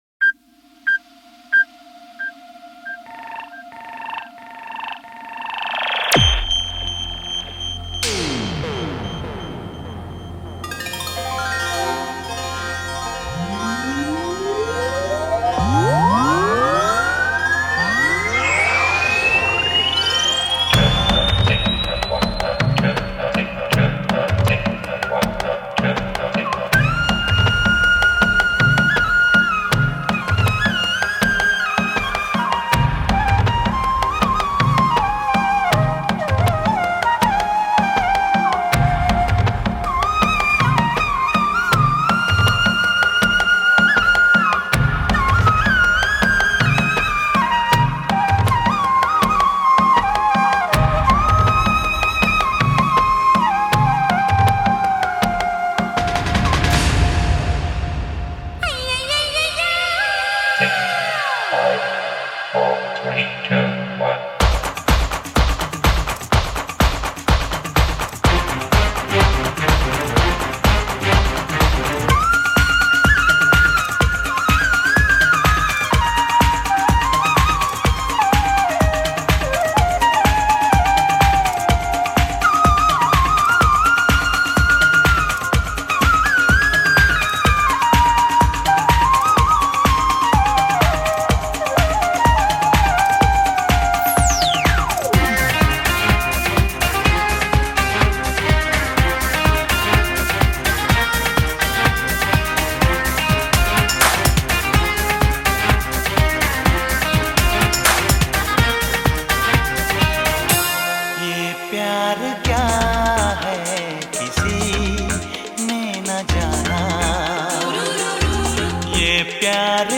Bollwood Songs